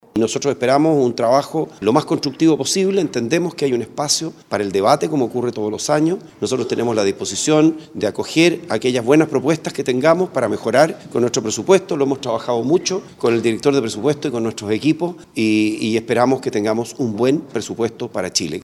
El ministro de Hacienda, Felipe Larraín, manifestó la disposición del Gobierno de acoger las propuestas para mejorar el proyecto por el bien del país.